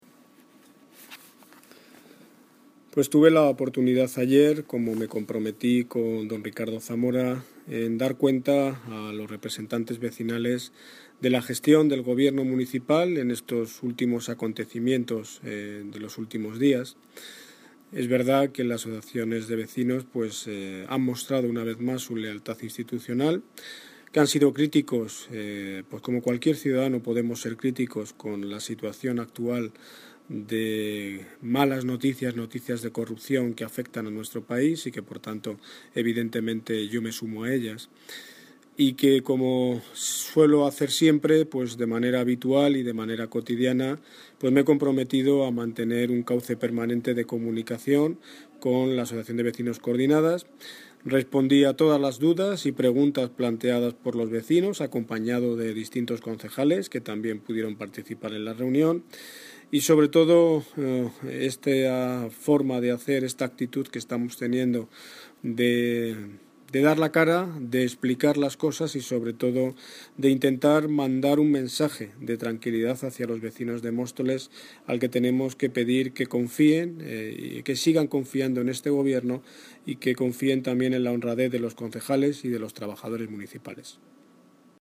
Audio - Daniel Ortiz (Alcalde de Móstoles) Sobre reunión con vecinos